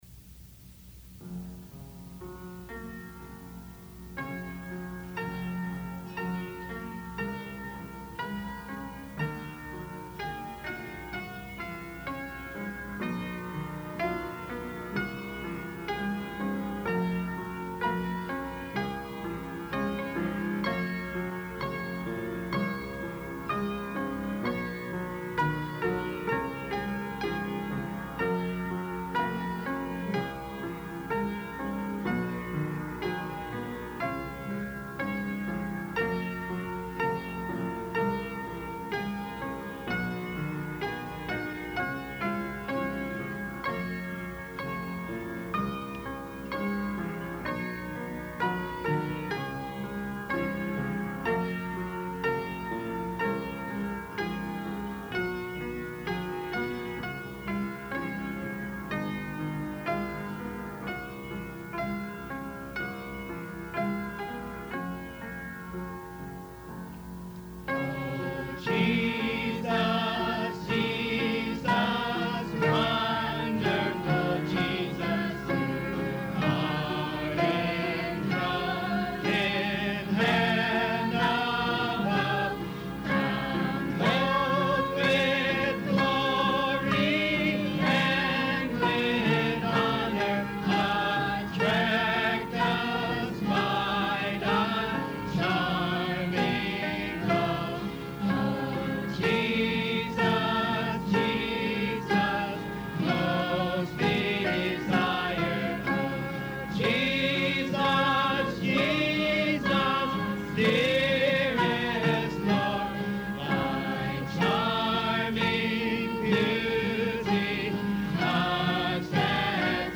E Major